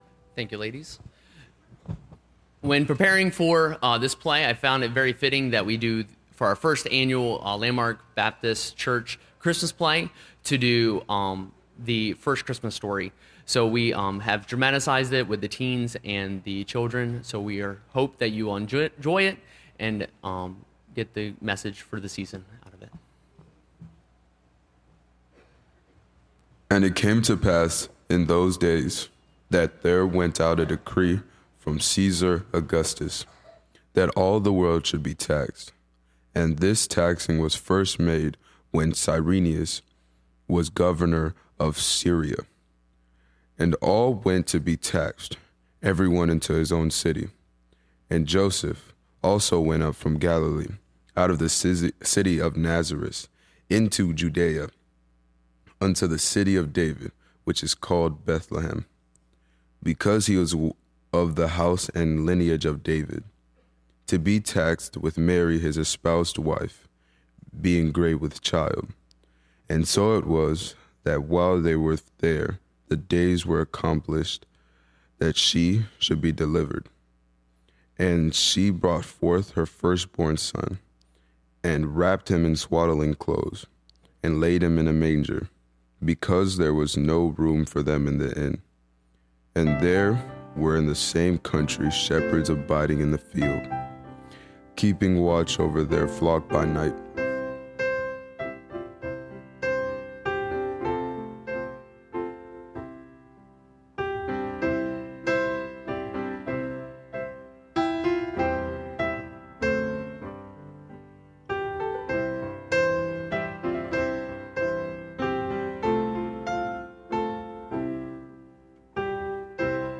Christmas Play – Landmark Baptist Church
Service Type: Sunday Morning